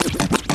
SOFT SCRATCH.wav